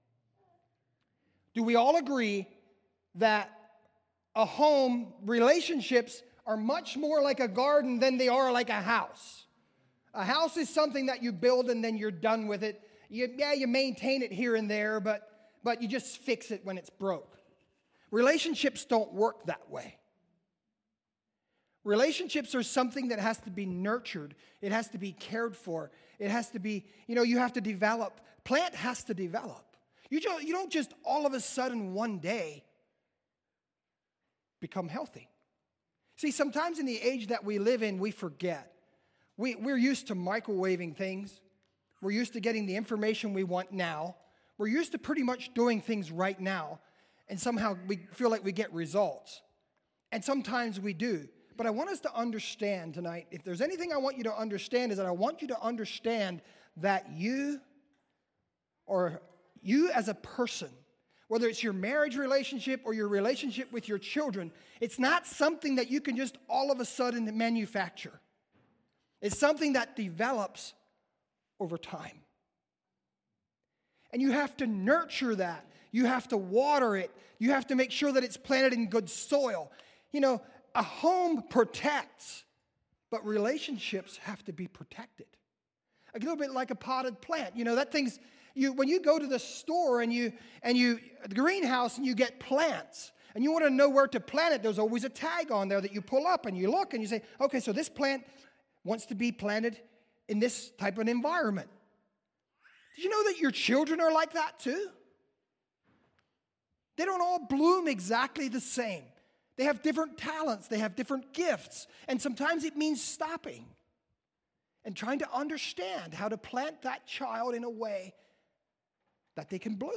Service Type: Special Meetings